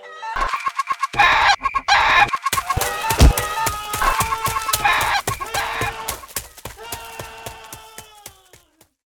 squeak1.ogg